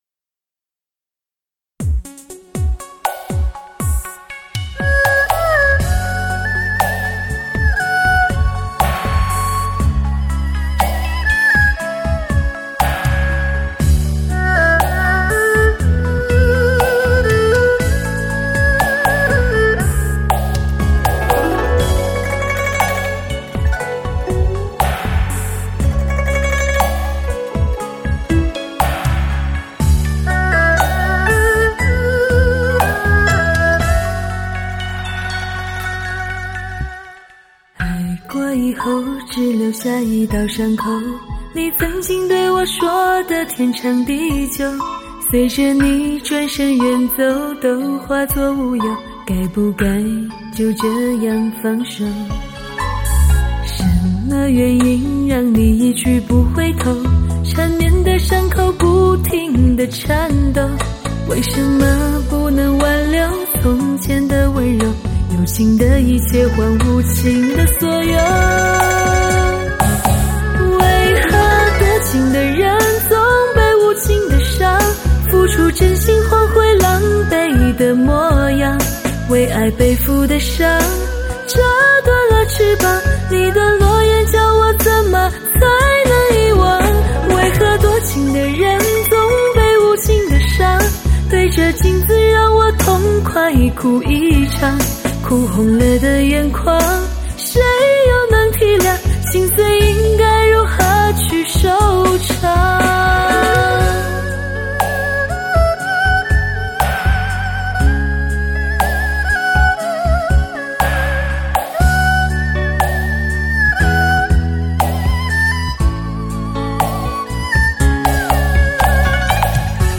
三十首女人唱的歌
最富声色吸引力的流行HIFI热曲
诱惑满分的金牌女声-极尽聆享魅力
完美迷人的真空录制-问鼎立体音效